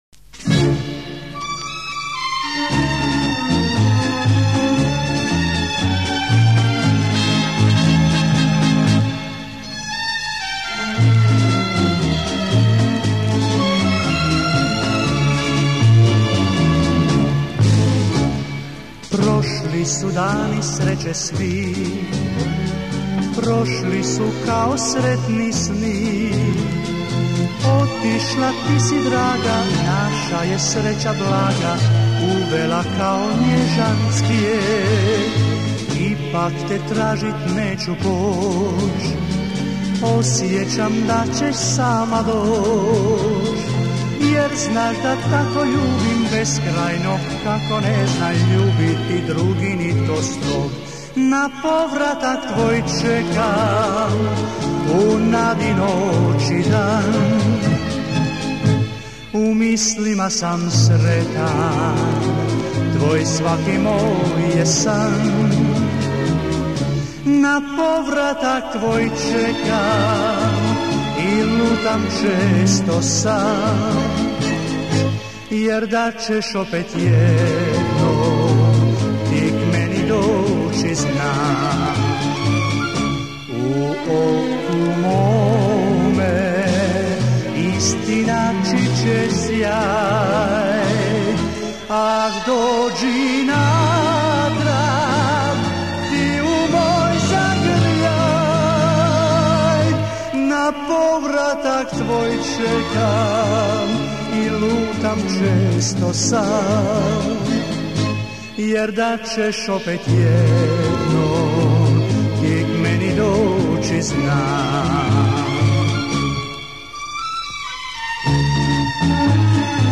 Популярное хорватское танго.